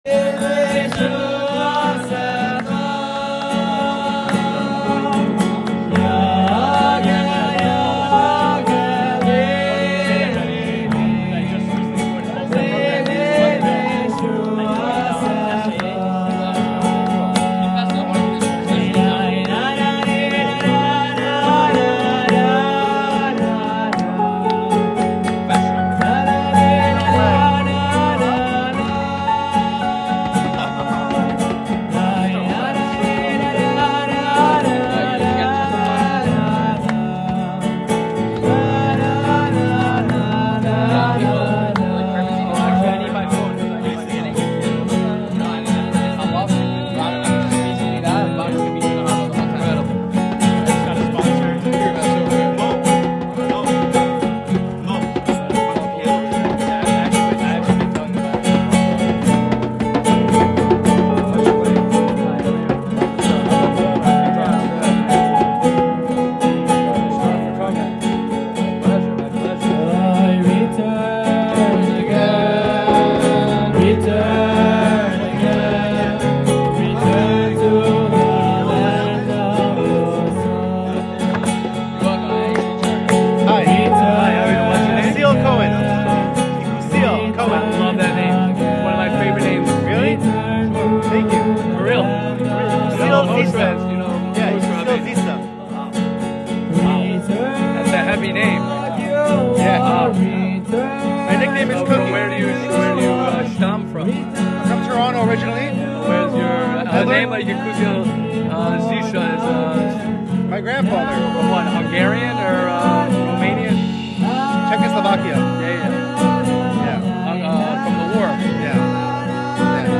Shiur @ The Shteeble Learning Program